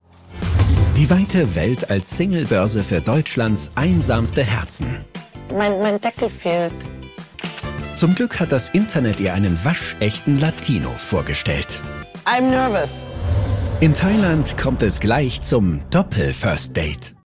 kommentar-joyn-amore unter palmen nr.1